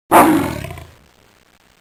aslan-sesi-kukremesi-ozel-egitim-ve-okul-oncesi-ses-taklitleri.mp3